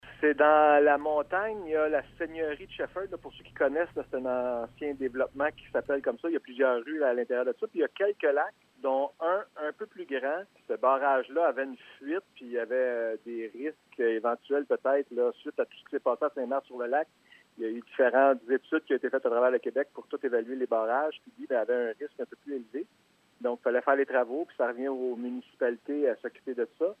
Le maire du canton de Shefford, Éric Gagnon.